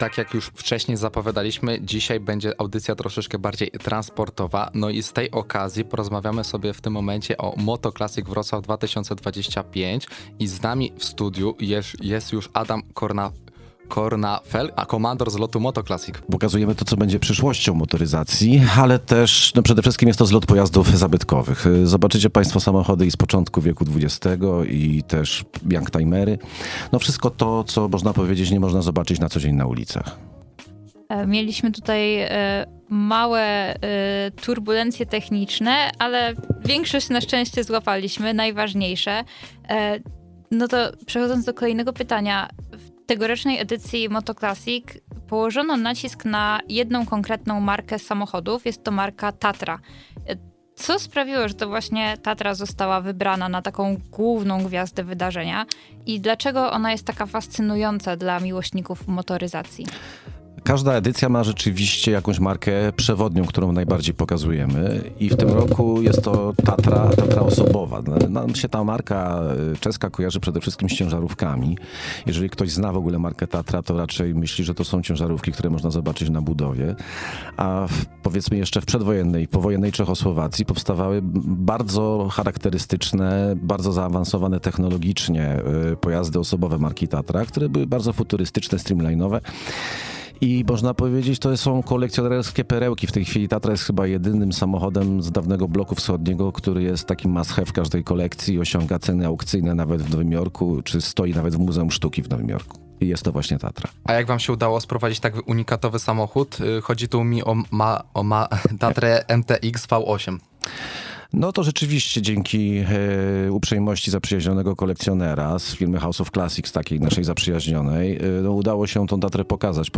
O wszystkich zaskoczeniach tegorocznej edycji rozmawiają